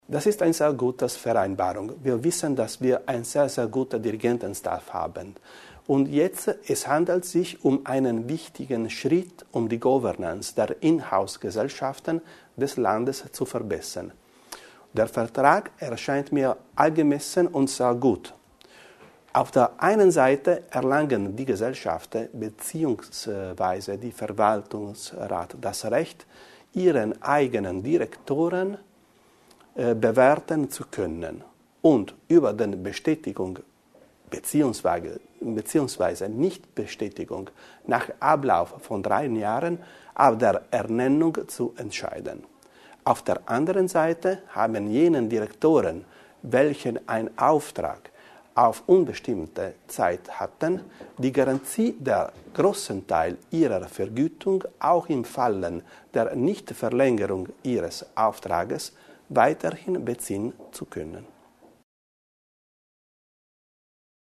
Landesrat Bizzo zur Bedeutung des Abkommens